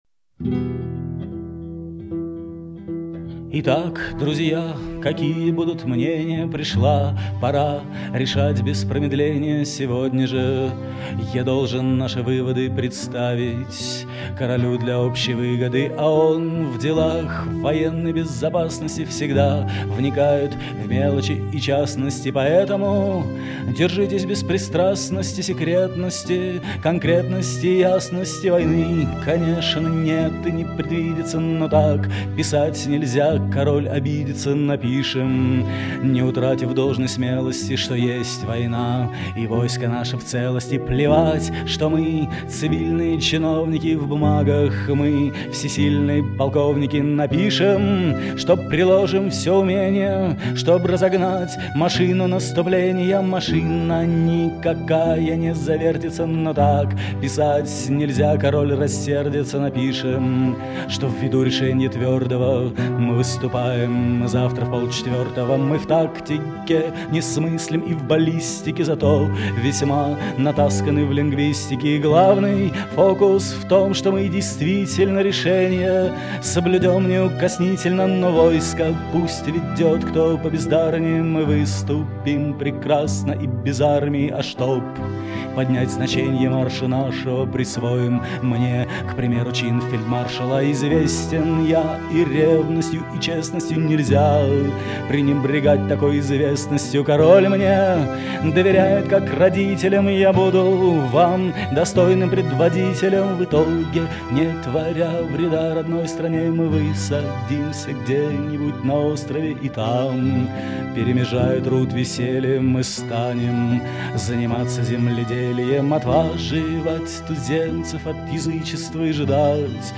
P.S. Вот например, ссылки на песни барда с ведущей аджной: